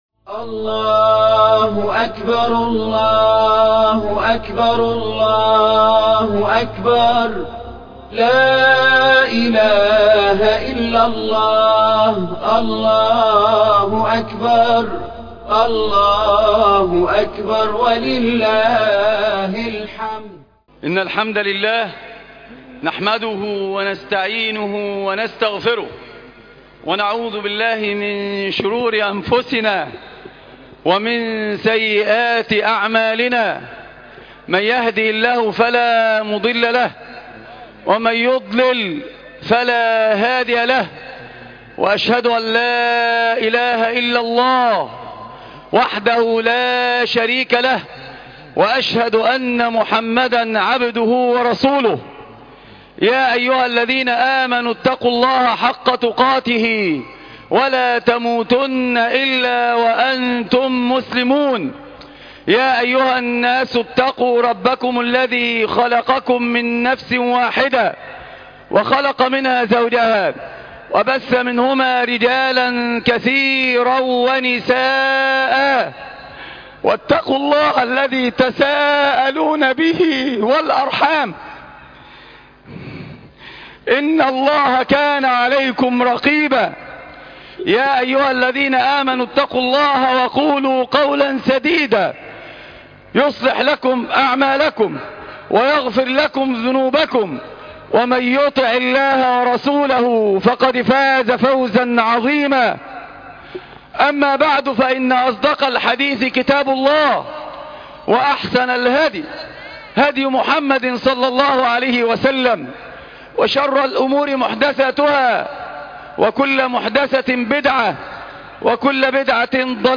خطبة عيد الفطر لعام 1434